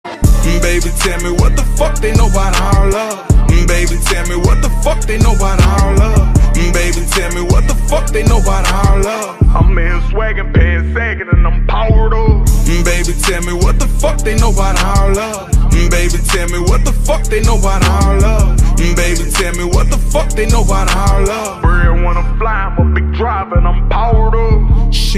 Rap - Hip Hop